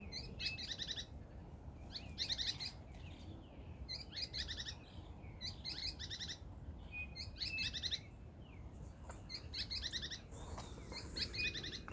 Spix´s Spinetail (Synallaxis spixi)
Location or protected area: Reserva Privada San Sebastián de la Selva
Condition: Wild
Certainty: Observed, Recorded vocal